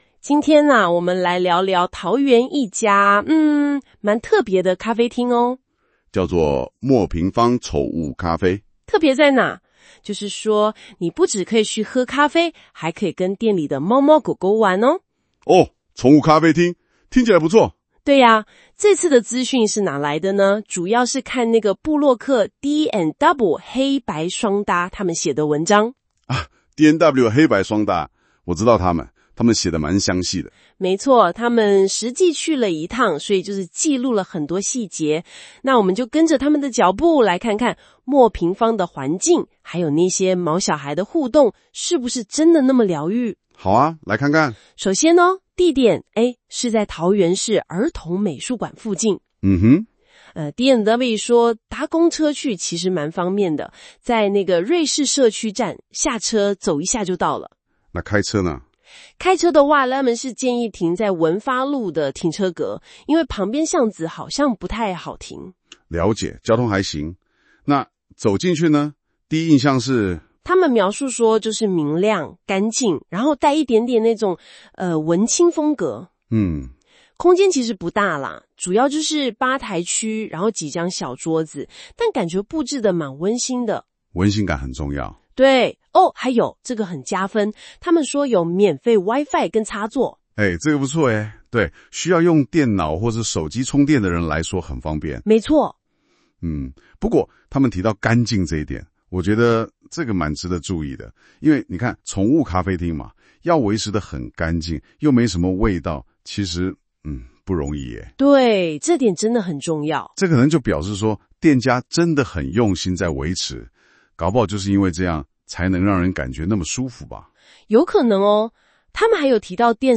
新功能!現在用【說】的方式介紹文章哦!